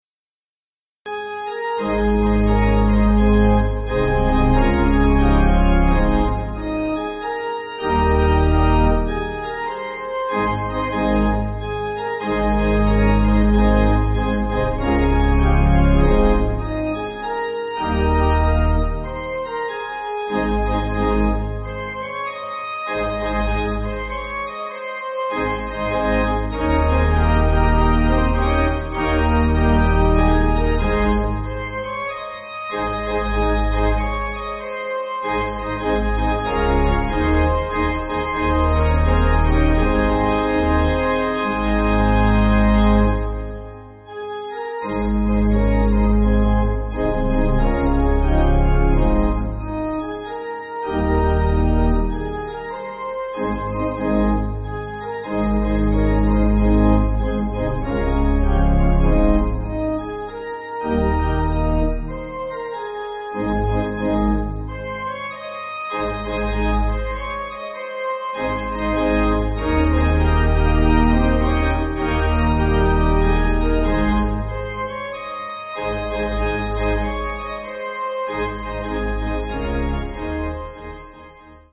(CM)   3/Ab